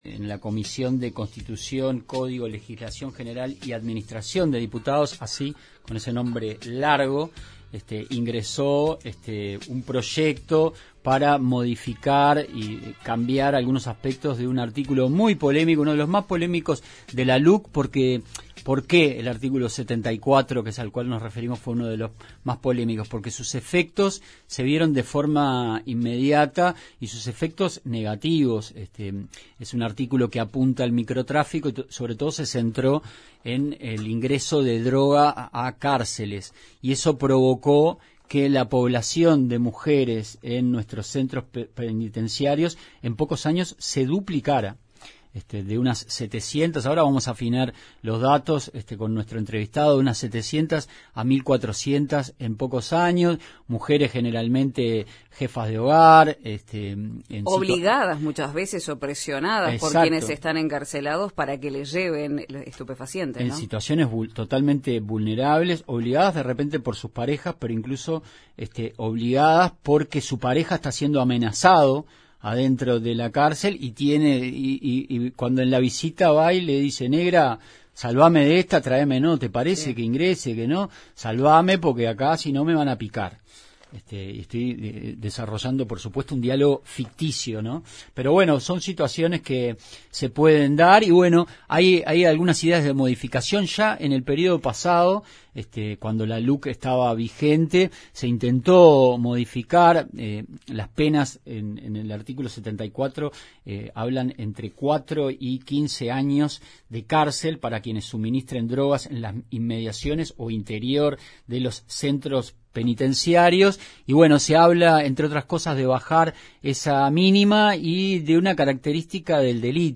Entrevista con Alejandro Zavala